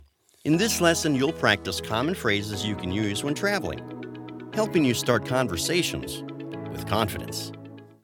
Explainer & Web Narration Demos
• A conversational, human delivery
• Broadcast-quality audio for animation or live-action
• A steady, confident tone for demos and how-tos